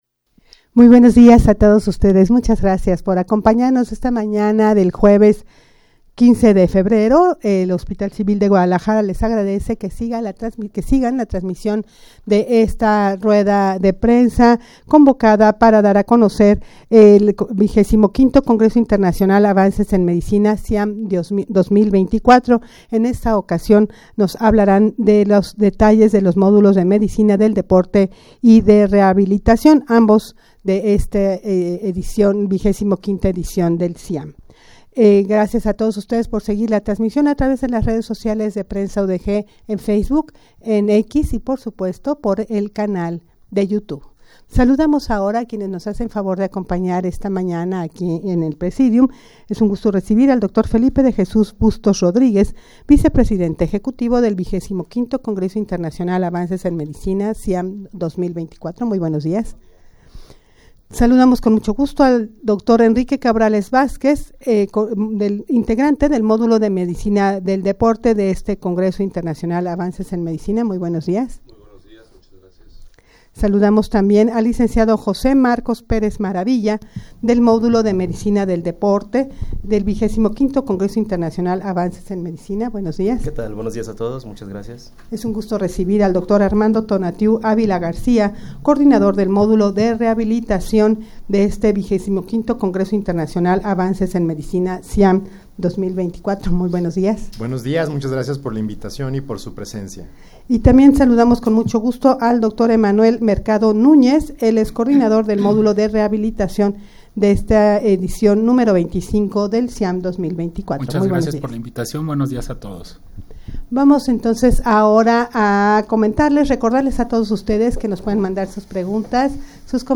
Audio de la Rueda de Prensa
rueda-de-prensa-para-dar-a-conocer-la-realizacion-de-los-modulos-de-medicina-del-deporte-y-de-rehabilitacion.mp3